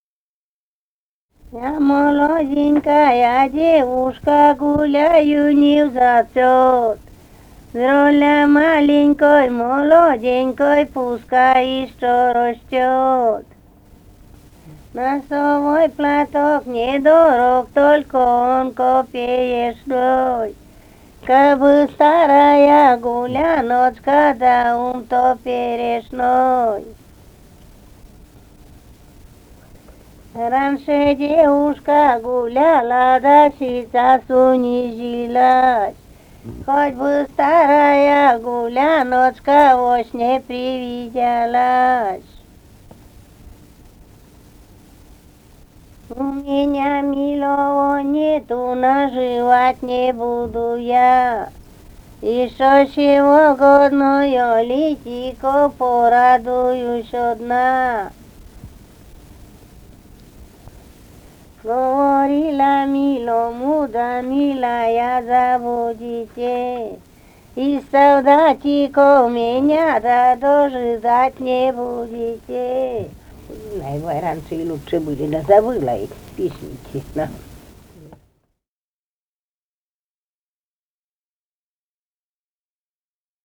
«Я, молоденькая девушка» (частушки).